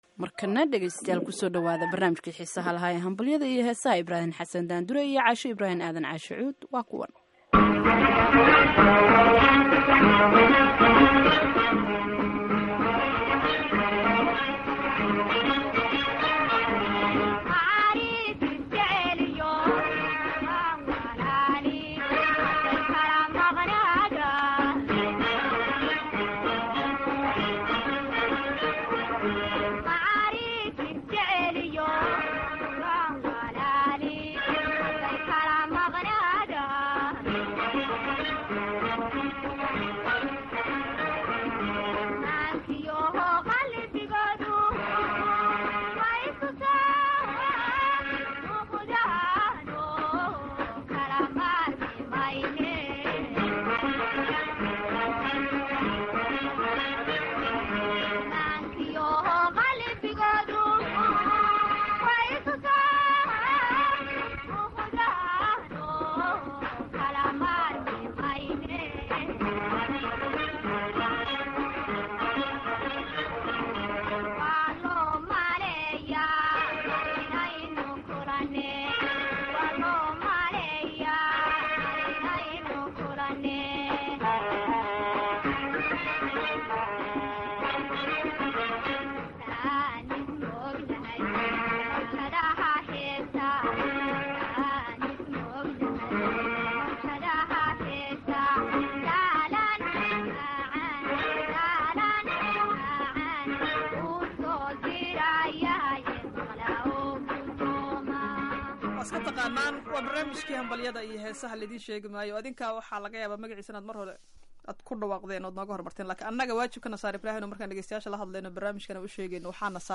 Barnaamijka Hambalyada iyo Heesaha ee toddobaadkan waxaa ku jira heeso macaan oo qaar badan oo idinka mid ah ay si wayn u jecleysan doonaan.